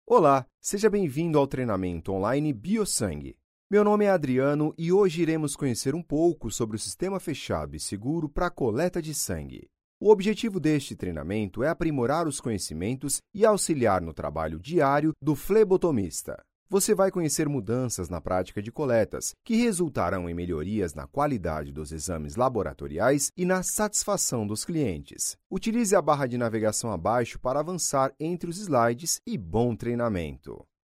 Voz Madura, Confiante, Natural, SimpĂĄtica, Acolhedora, Segura, Jovem, Coloquial, Conversada, Caricata.
Sprechprobe: eLearning (Muttersprache):